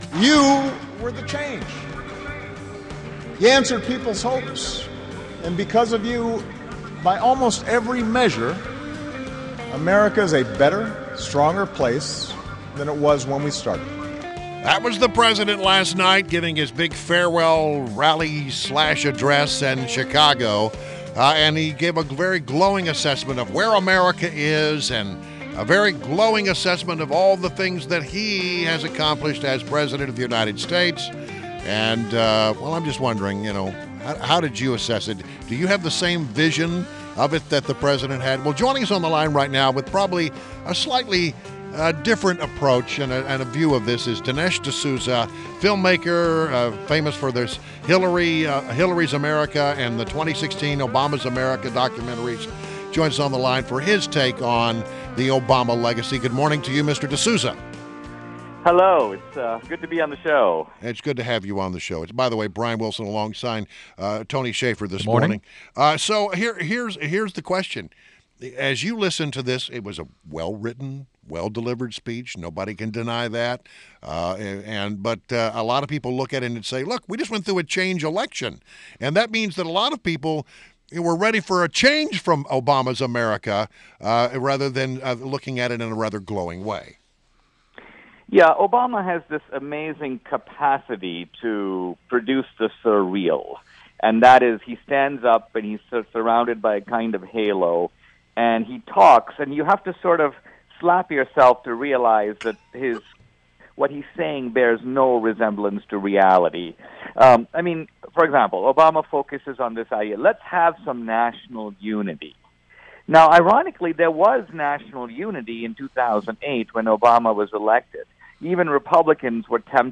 WMAL Interview - DINESH D'SOUZA - 01.11.17